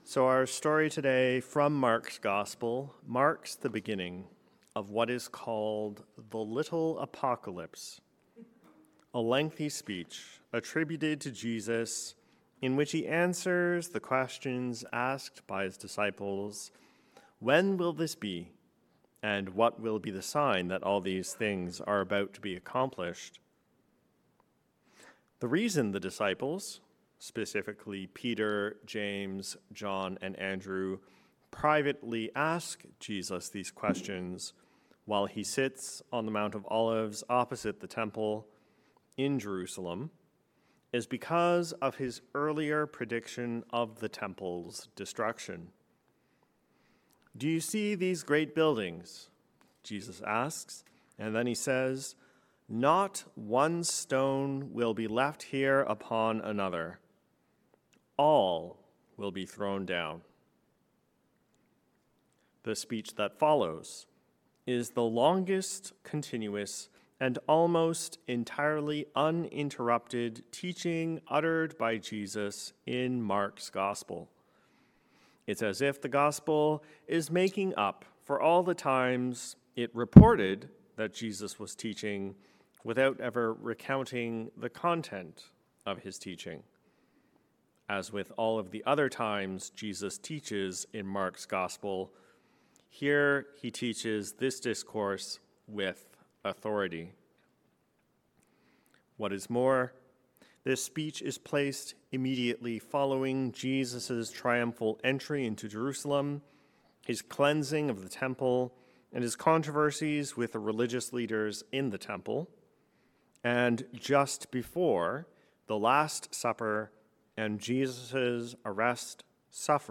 This is not the end. A sermon on Mark 13:1-8